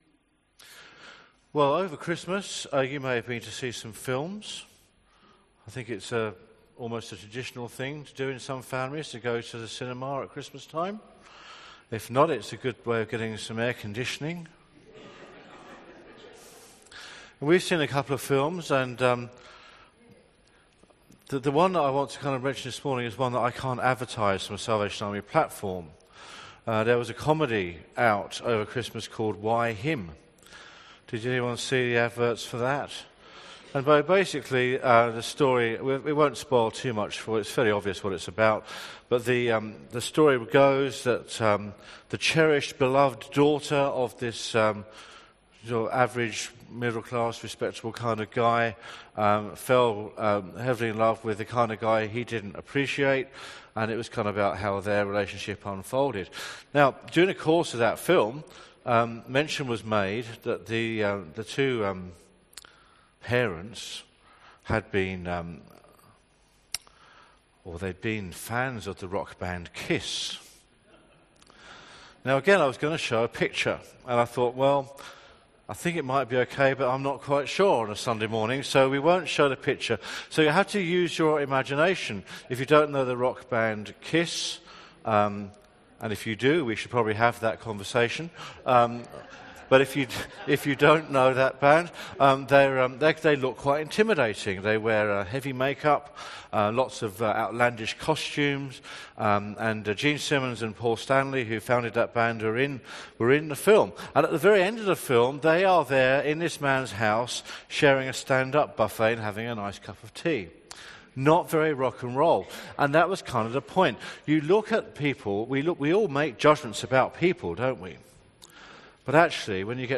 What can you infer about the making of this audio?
Sermon from the 10AM meeting at Newcastle Worship & Community Centre of The Salvation Army on 08/01/2017. The related Bible reading was Matthew 3:13-17.